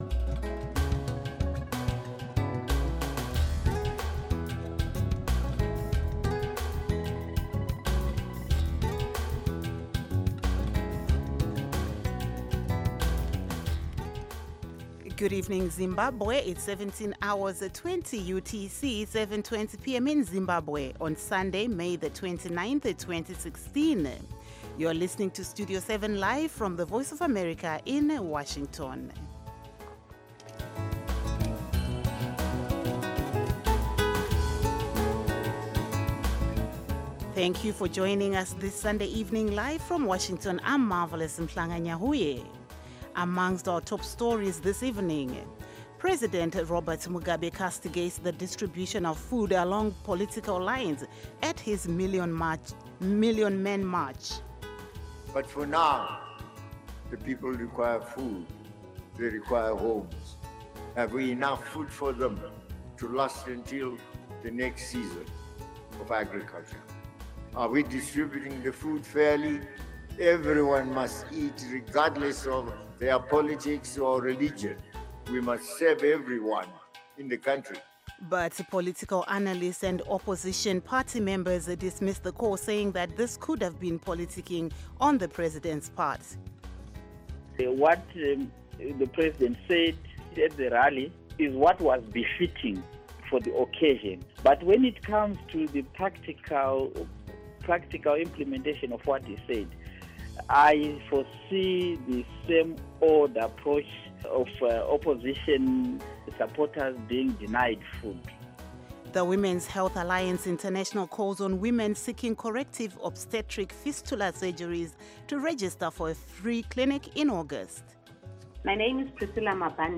News in English